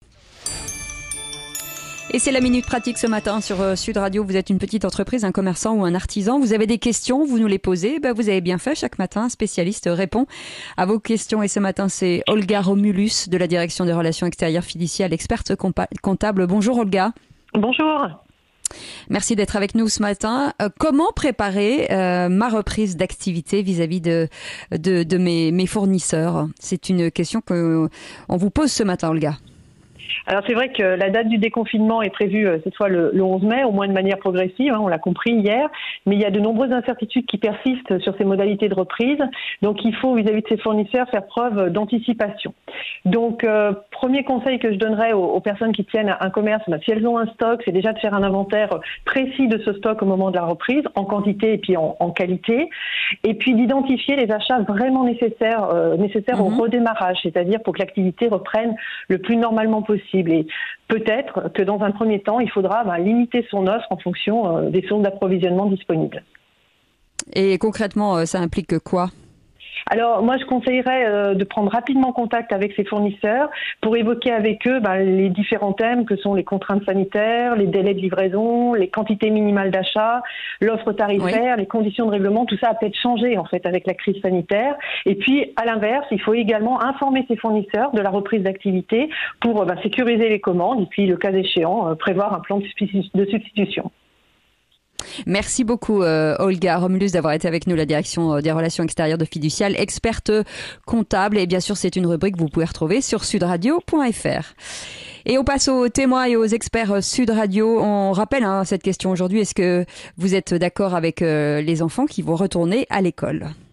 La minute pratique - A 9 heures, chaque jour dans le Grand Matin Sud Radio, des spécialistes Fiducial vous répondent.